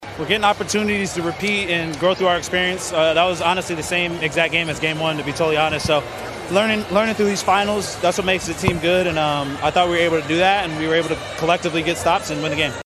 Williams Postgame on Stops 6-17.mp3